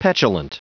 Prononciation du mot petulant en anglais (fichier audio)
Prononciation du mot : petulant